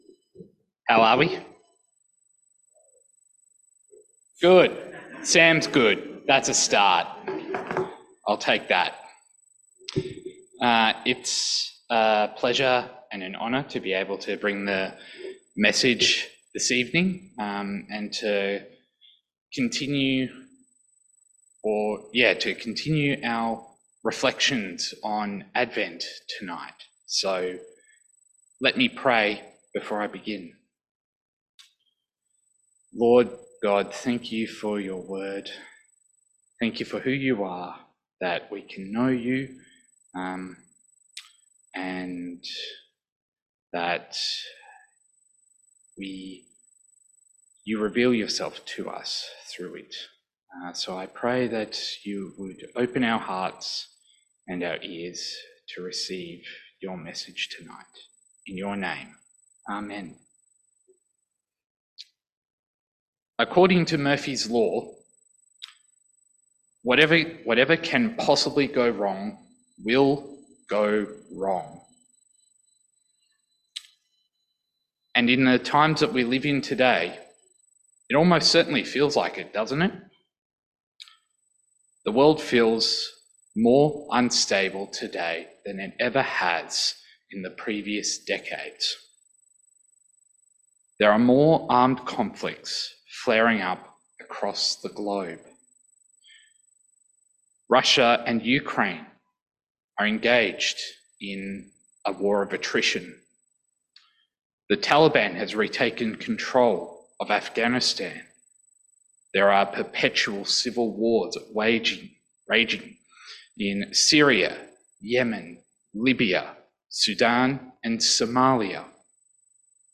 Passage: Luke 1:26-38 Service Type: 5:30pm Resonate Service « Advent 2